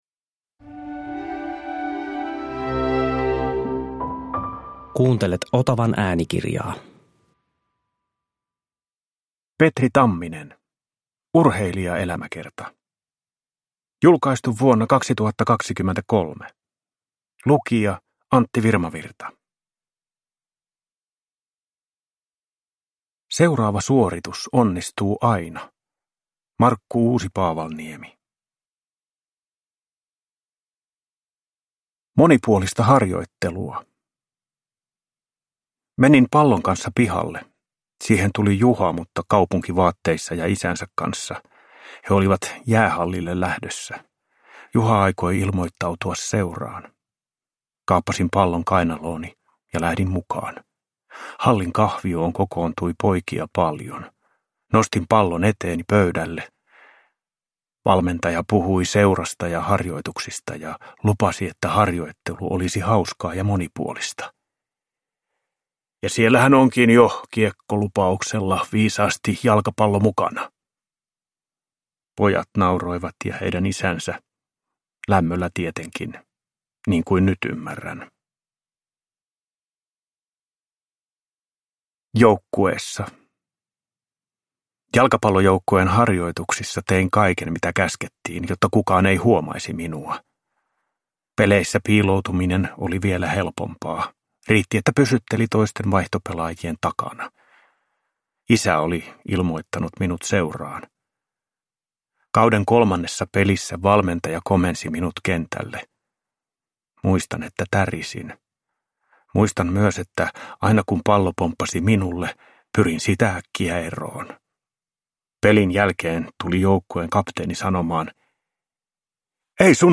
Uppläsare: Antti Virmavirta